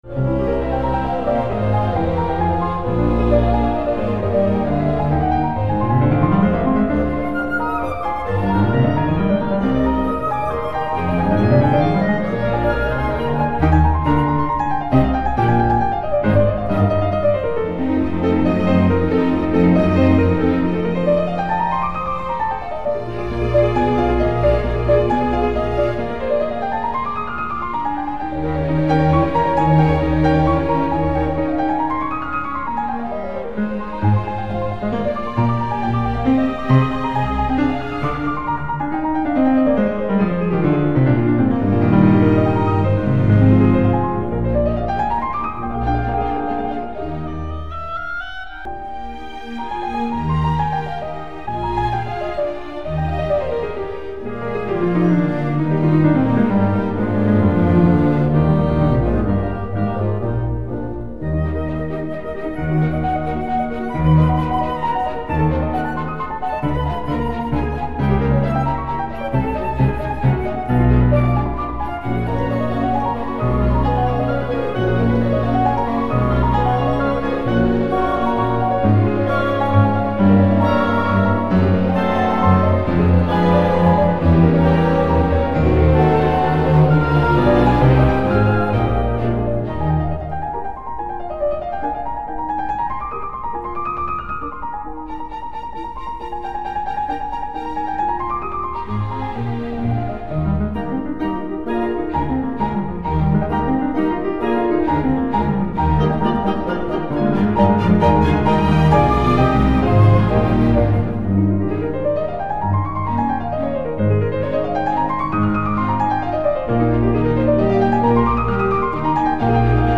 MOZART, concerto pour piano n24 k491, 01 allegro (extr)